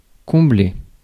Ääntäminen
IPA: /kɔ̃.ble/